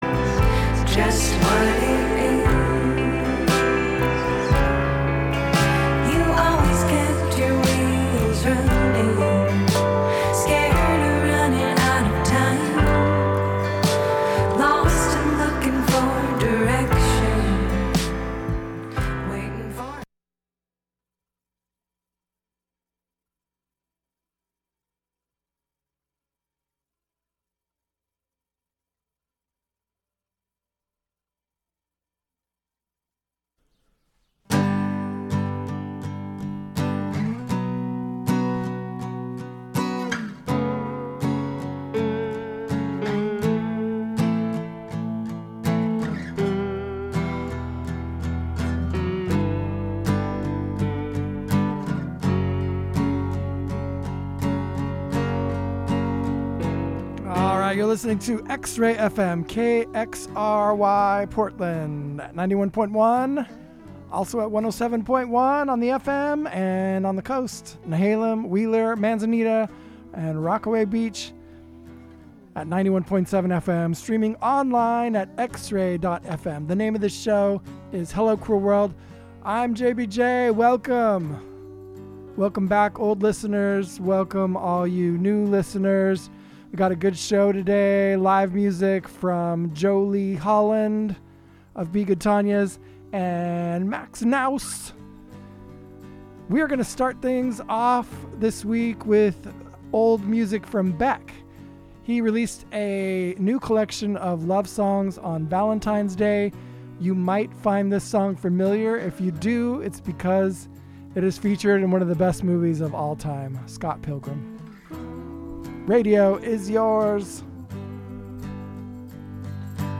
Hello Cruel World brings the worlds of singer/songwriters and acoustic music to you every Thursday from 3-4pm with conversations and in-studio performances as often as possible.